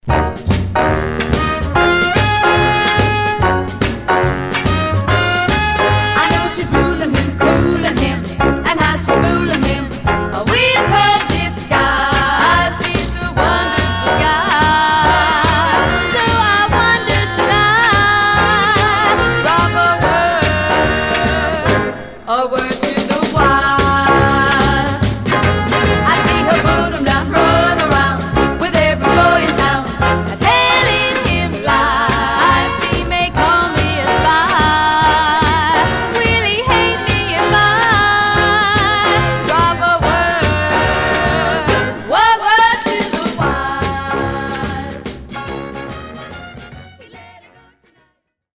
Some surface noise/wear
Mono
White Teen Girl Groups